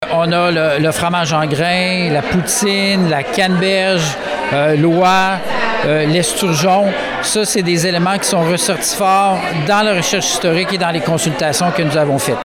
Cette étude a été présentée mercredi en conférence de presse.